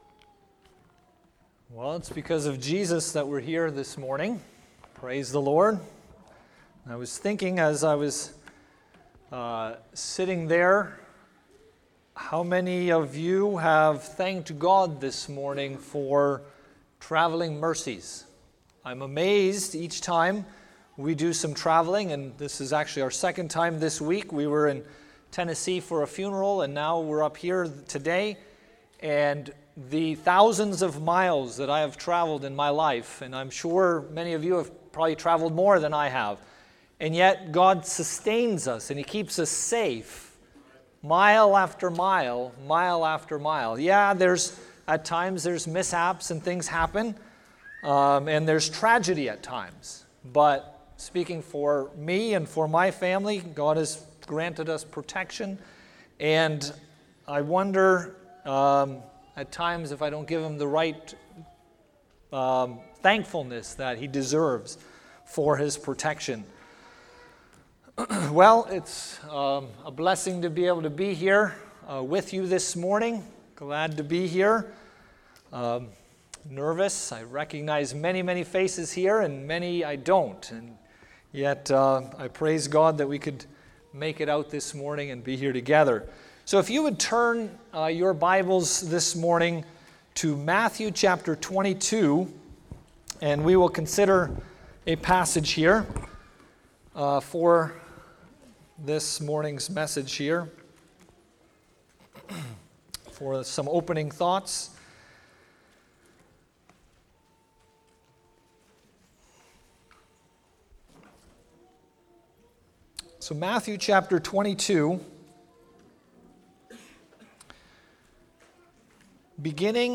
Fellowship Weekend Service Type: Sunday Morning %todo_render% « The Strait & Narrow Way
sermon 1.mp3